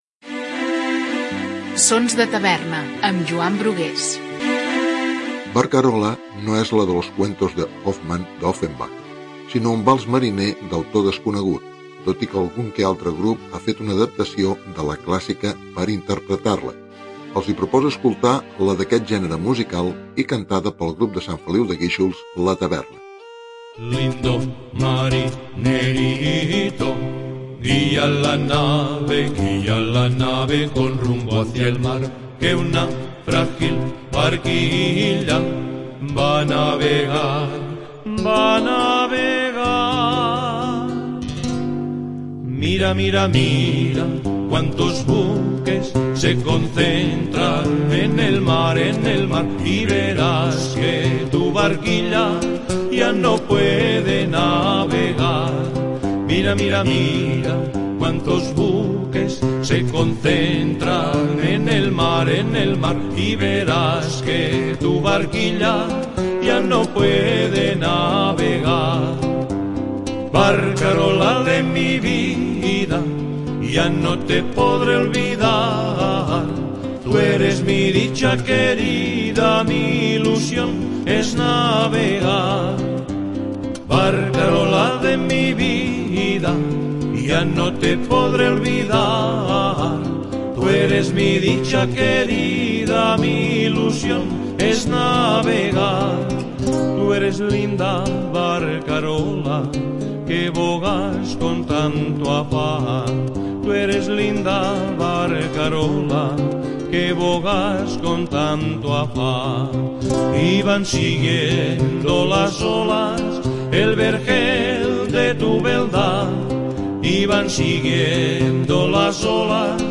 sinó un vals mariner d’autor desconegut